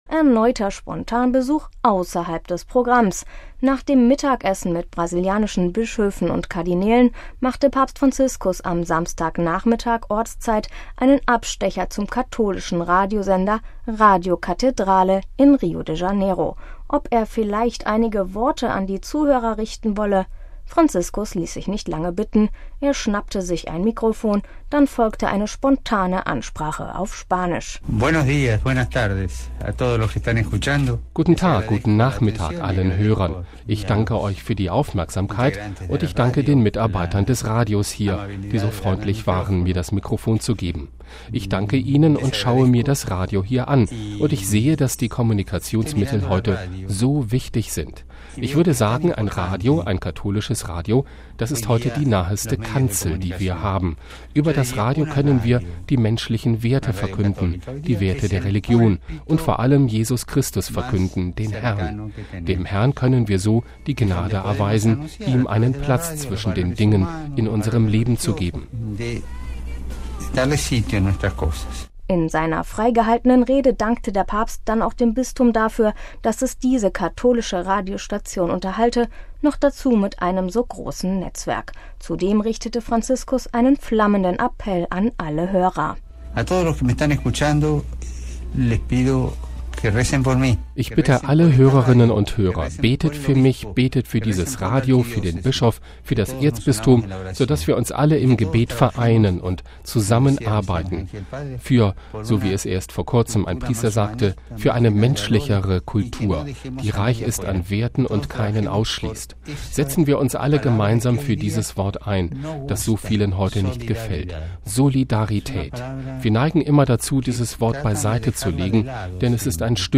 MP3 Erneuter Spontanbesuch außerhalb des Programms: Nach dem Mittagessen mit brasilianischen Bischöfen und Kardinälen machte Papst Franziskus am Samstagnachmittag (Ortszeit) einen Abstecher zum katholischen Radiosender „Radio Cattedrale“ in Rio de Janeiro.
Franziskus ließ sich nicht lange bitten: Er schnappte sich ein Mikrofon, dann folgte eine spontane Ansprache auf Spanisch: